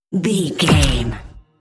Sci fi gun shot whoosh fast
Sound Effects
Fast
futuristic
whoosh